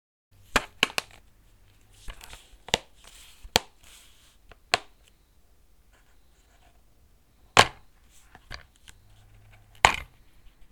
Plastic-DVD-case-handling-open-and-close-2.mp3